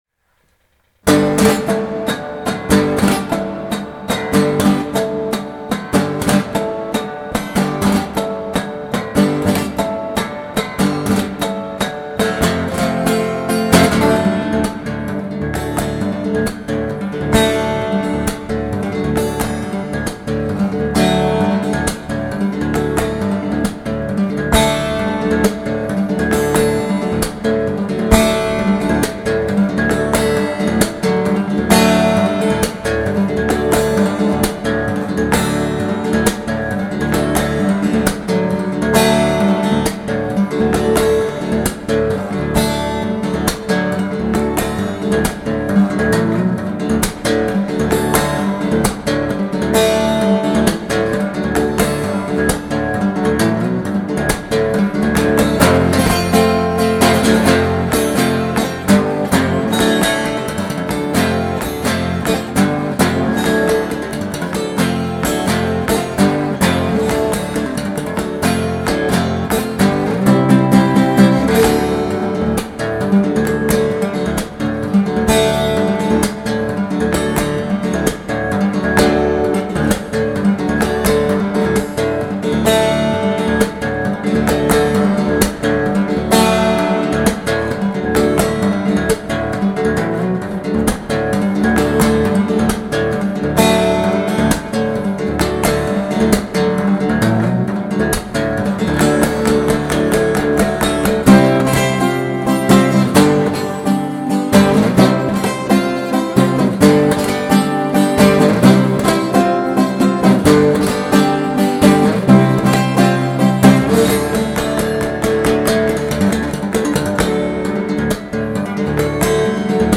An extremely talented young guitarist in Kyoto